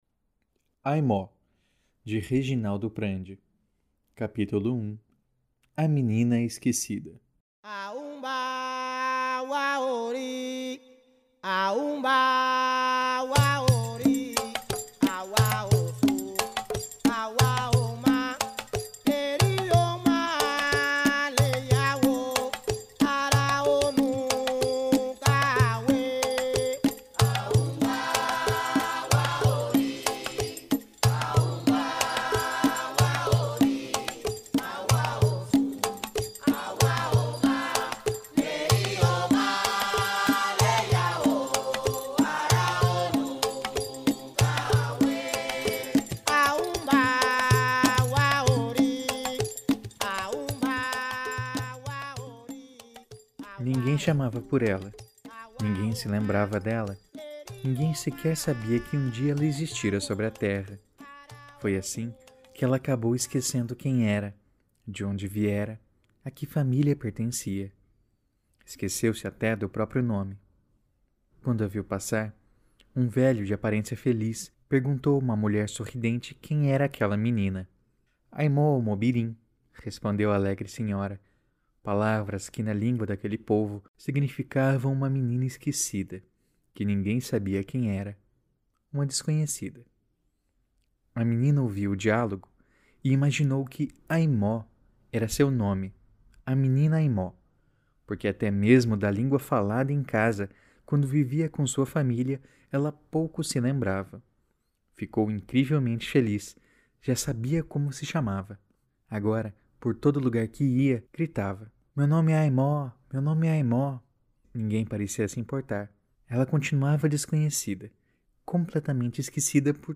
A começar pela dramatização do primeiro capítulo do livro Aimó, de Reginaldo Prandi.
– Canto de abertura e encerramento do povo Ashaninka – A história abre com um canto canto aos Eguns , como é o caso de Aimó Este podcast é produzido graças aos apoiadores do PADRIM .